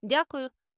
DYAH-koo-yoo thank you